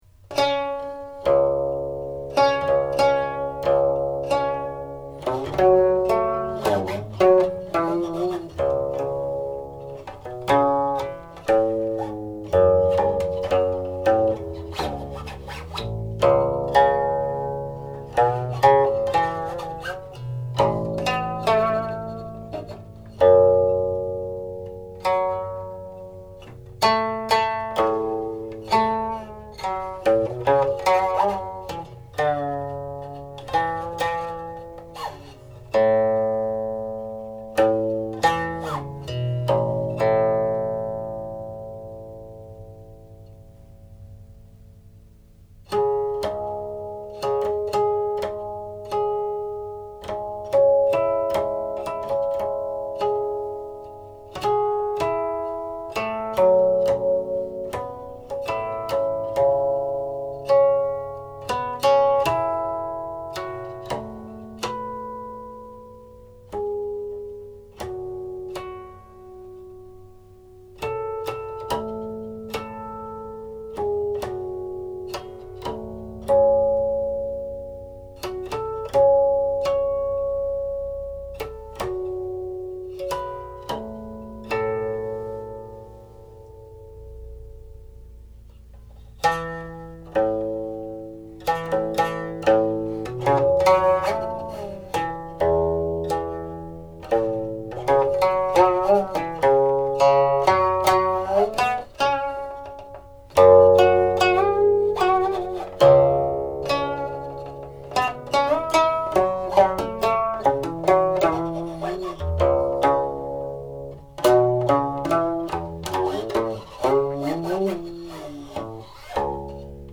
00.45   2. (harmonics)
02.10         harmonic coda
From standard tuning lower the first and raise the fifth strings a half step each.